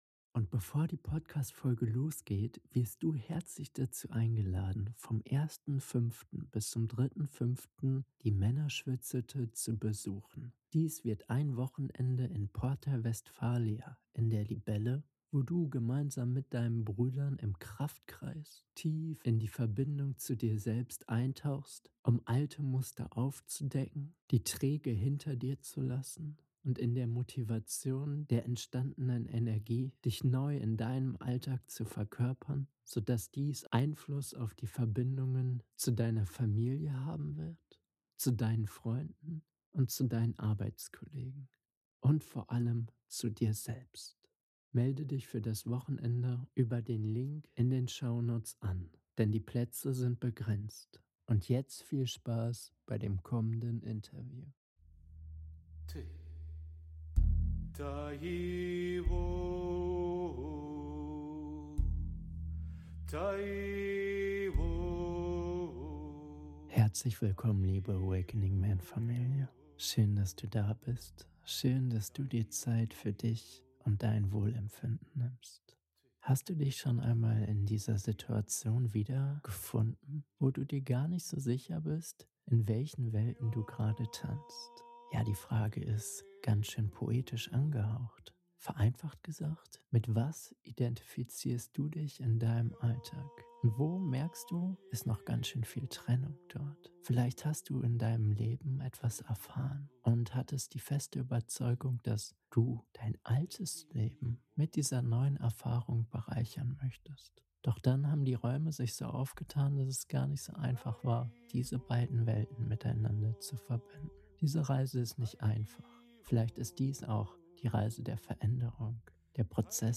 Vom Verstehen ins Sein - Interview